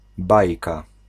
Ääntäminen
US : IPA : [ˈfeɪ.bl̩]